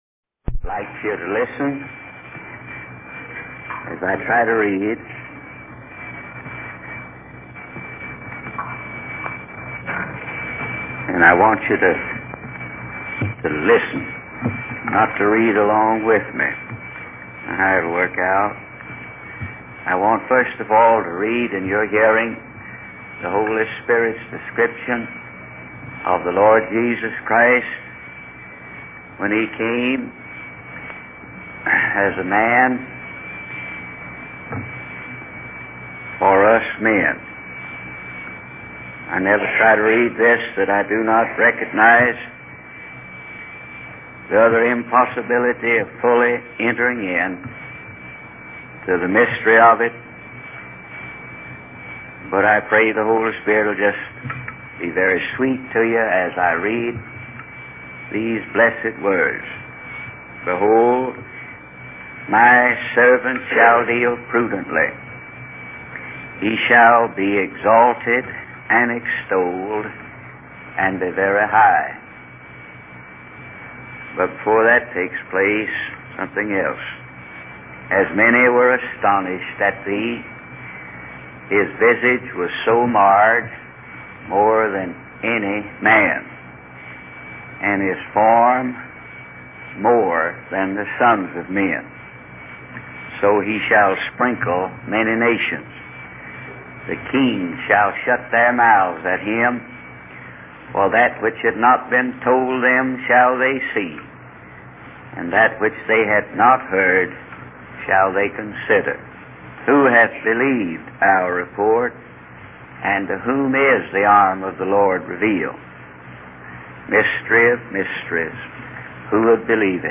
In this sermon, the speaker reflects on the significance of Jesus Christ in history and the importance of the Bible in understanding his life and purpose. The speaker emphasizes the need to surrender oneself completely to God and to find salvation in Him.